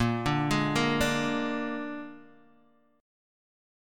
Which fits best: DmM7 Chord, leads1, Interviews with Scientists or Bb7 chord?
Bb7 chord